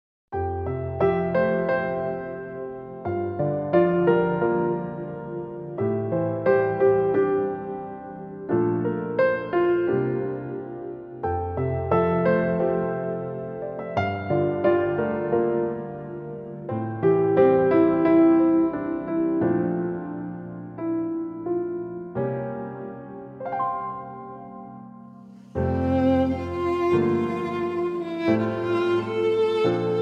Instrumental
Violinistin